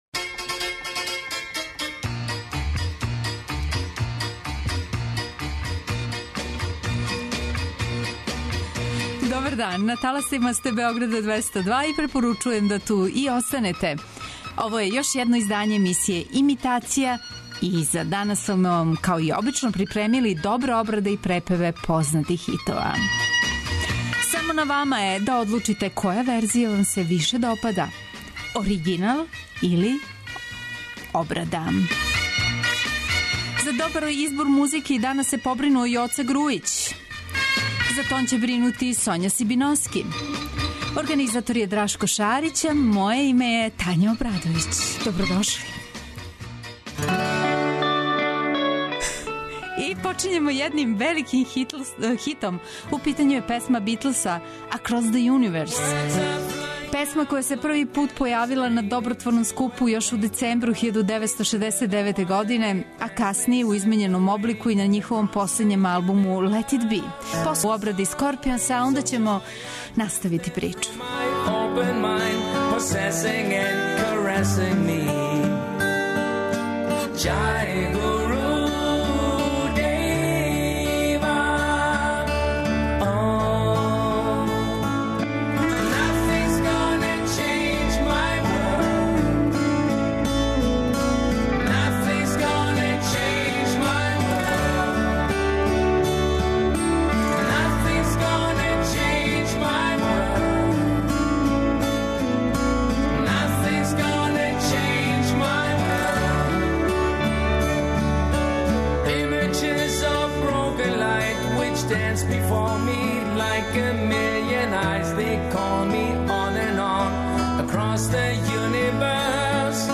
преузми : 29.17 MB Имитација Autor: Београд 202 Имитација је емисија у којој се емитују обраде познатих хитова домаће и иностране музике.